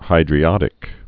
(hīdrē-ŏdĭk)